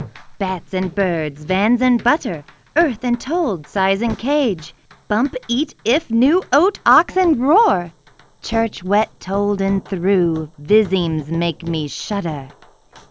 This problem deals with adaptive noise cancellation using the LMS algorithm.